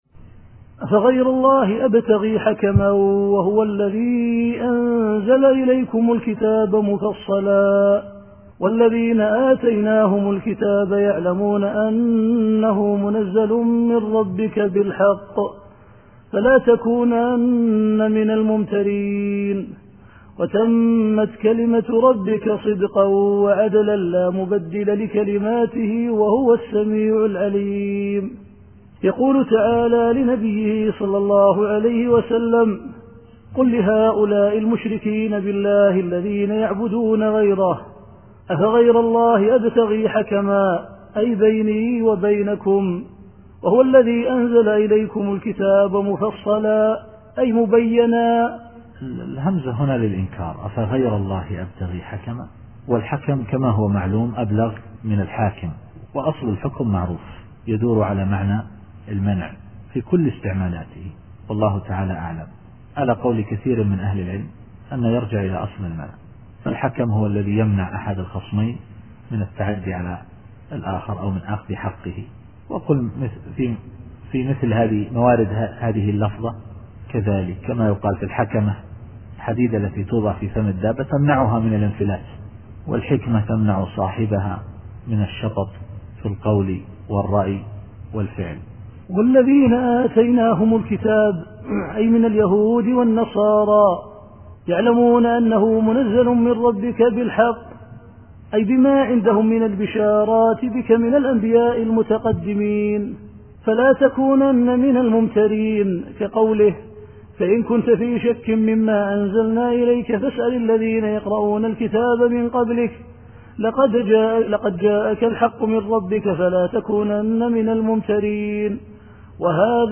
التفسير الصوتي [الأنعام / 114]